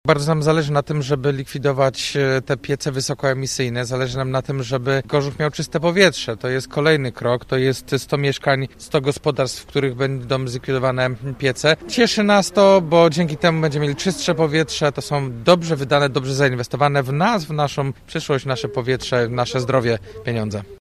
Po poprzednim programie „Kawka”, w ramach którego z obszaru Gorzowa zlikwidowano znaczną ilość tzw. kopciuchów, teraz mamy szansę na kolejne modernizacje – mówi prezydent Gorzowa, Jacek Wójcicki.